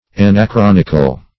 Search Result for " anachronical" : The Collaborative International Dictionary of English v.0.48: Anachronic \An`a*chron"ic\, Anachronical \An`a*chron"ic*al\,a. Characterized by, or involving, anachronism; anachronistic.